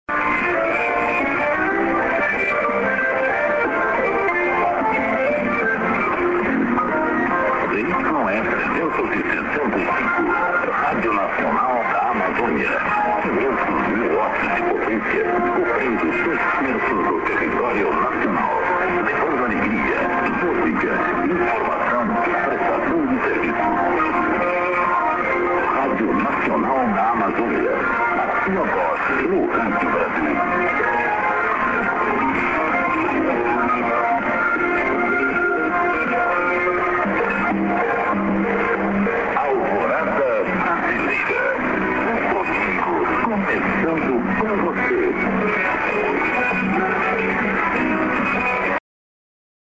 music->ID+ADDR(men)->music